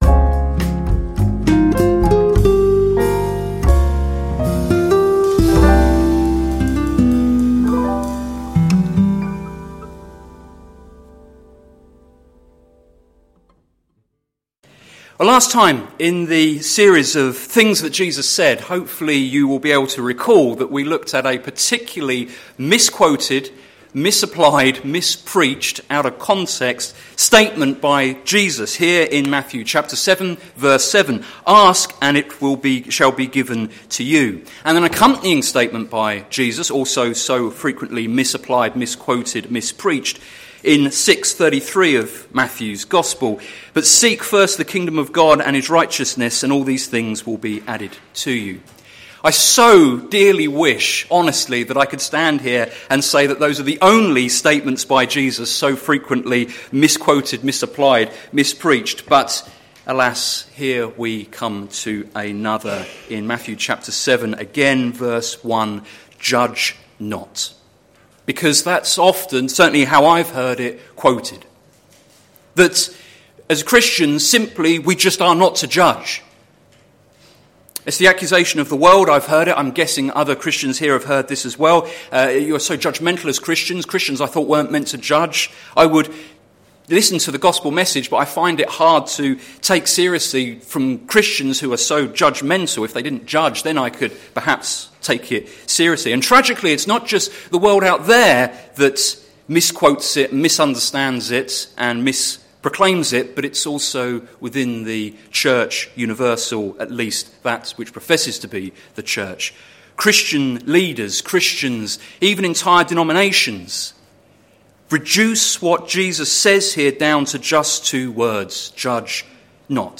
Sermon Series - Things Jesus said - plfc (Pound Lane Free Church, Isleham, Cambridgeshire)